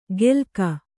♪ gelka